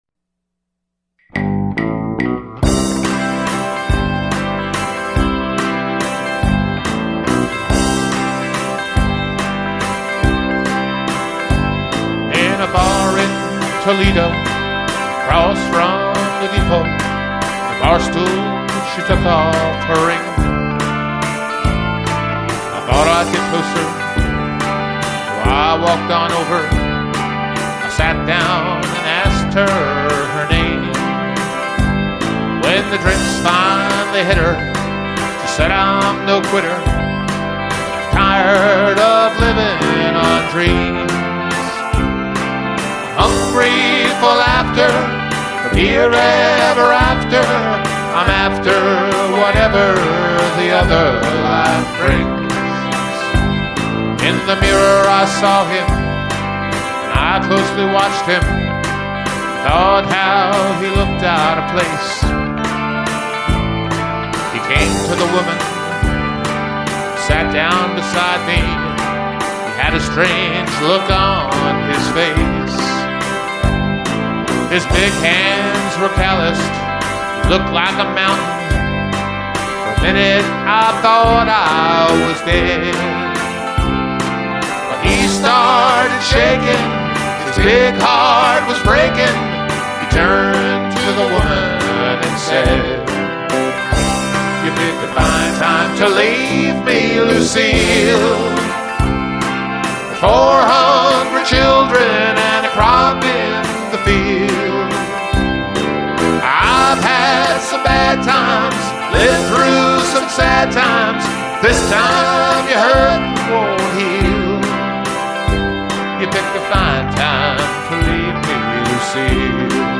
Music Collections: A Touch of Country